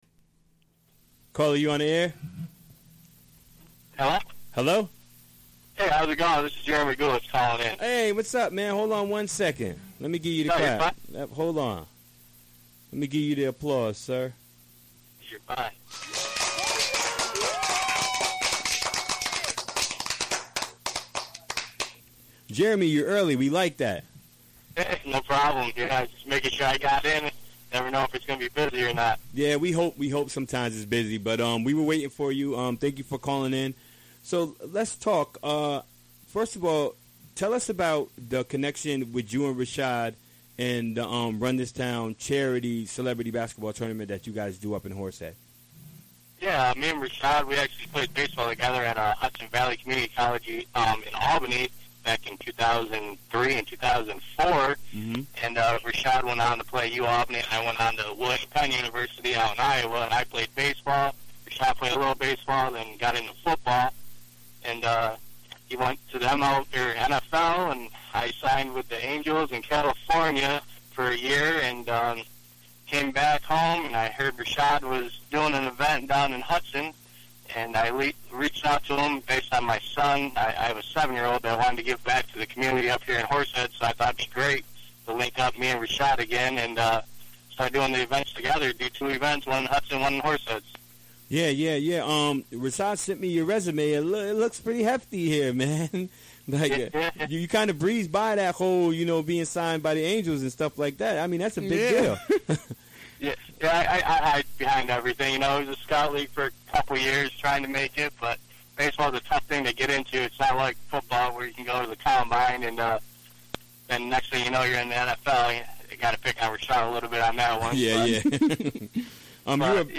Recorded live, via telephone during the WGXC Afternoon Show Wed., Apr. 5, 2017.